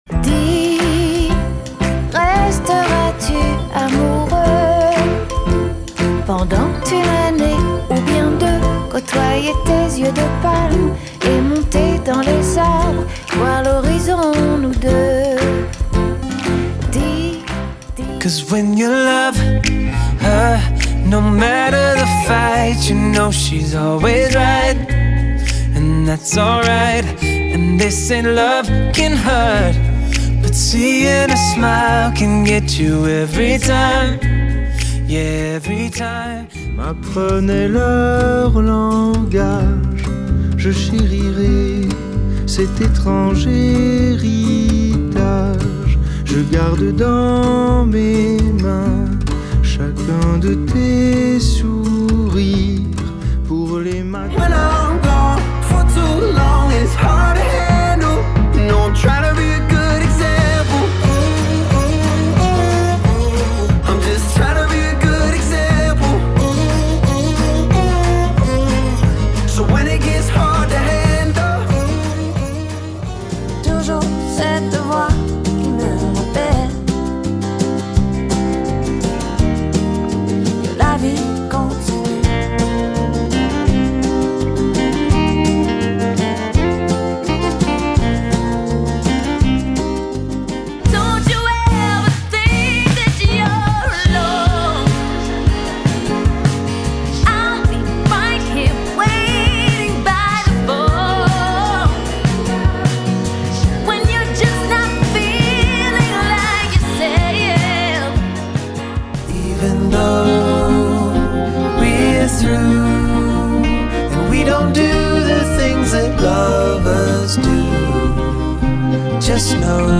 Description: French-Canadian and English Adult Contemporary.